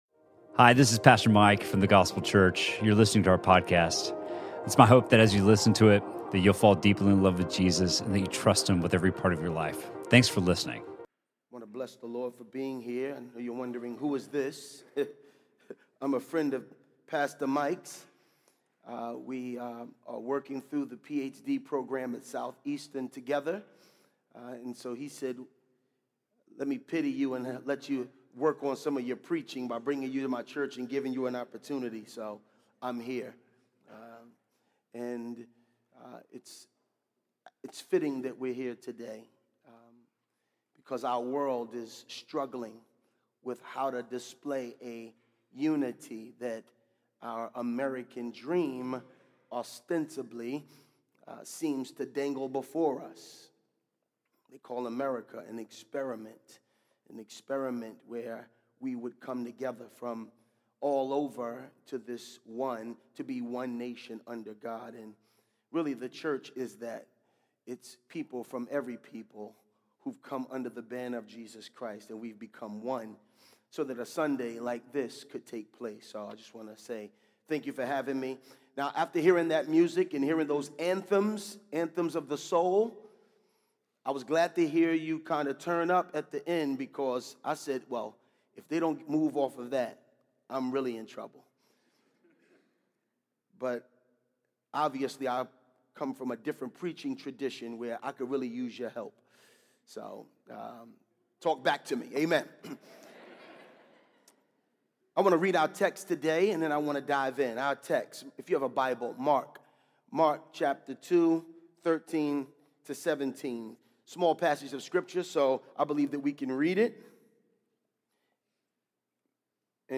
Sermon from The Gospel Church on March 10rd